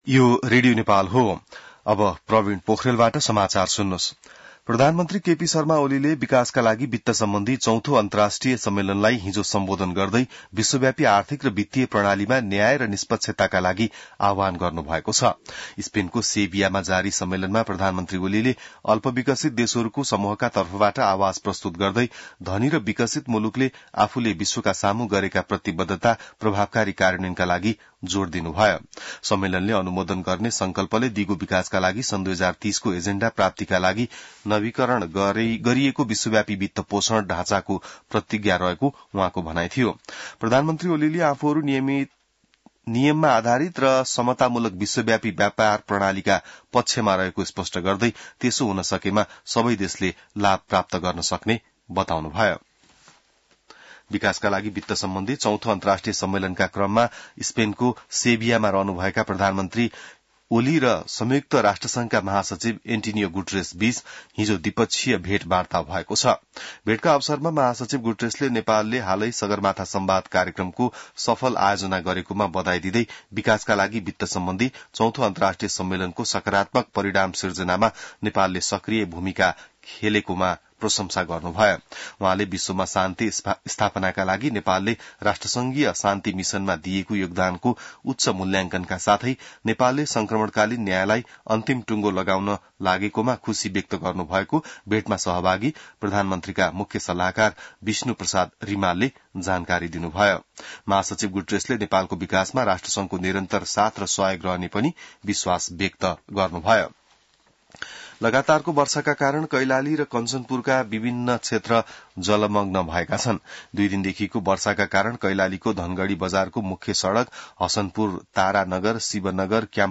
बिहान ६ बजेको नेपाली समाचार : १७ असार , २०८२